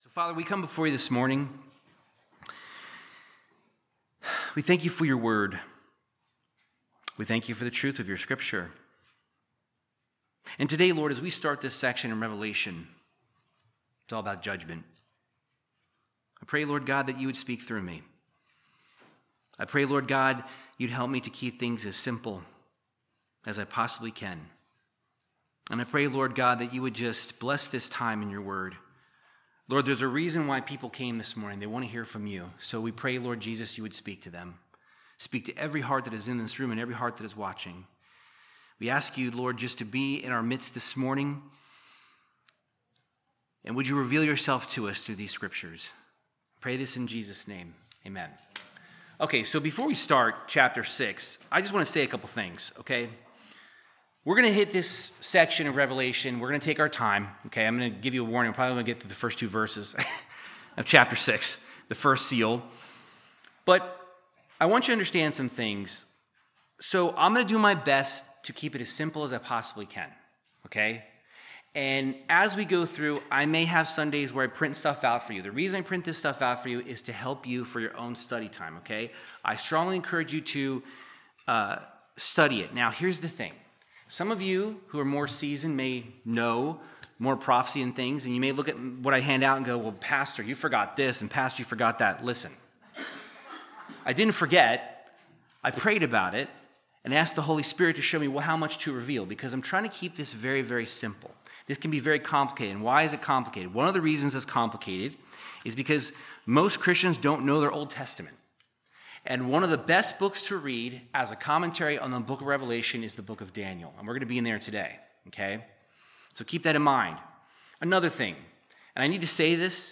Verse by verse Bible teaching through the book of Revelation chapter 6